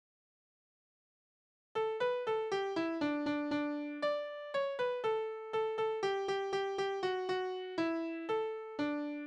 Necklieder: Große Frau und kleiner Mann
Tonart: D-Dur
Tonumfang: Oktave
Besetzung: vokal